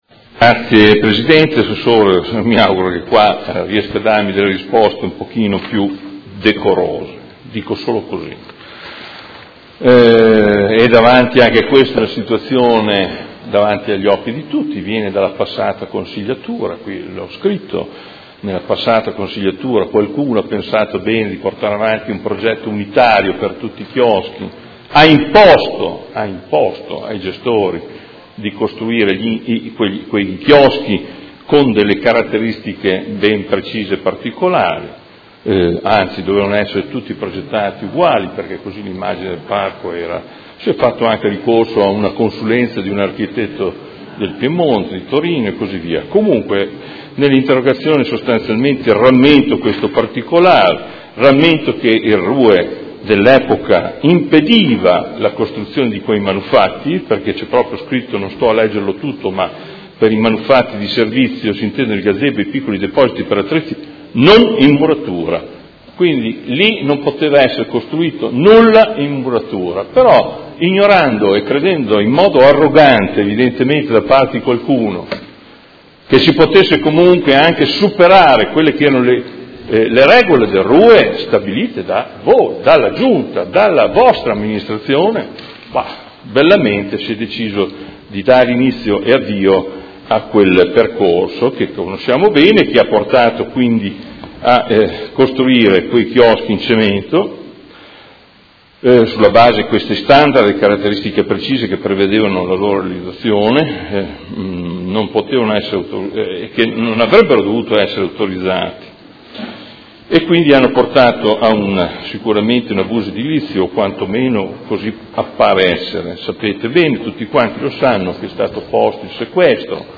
Seduta del 20/04/2017. Interrogazione del Consigliere Morandi (FI) avente per oggetto: Il blocco nella costruzione dei chioschi del Parco delle Rimembranze sono un problema serio, causato dalla precedente amministrazione, a cui occorre con urgenza trovare le soluzioni di sblocco. Quali progetti propone l’attuale amministrazione?